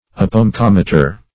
Search Result for " apomecometer" : The Collaborative International Dictionary of English v.0.48: Apomecometer \Ap`o*me*com"e*ter\, n. An instrument for measuring the height of objects.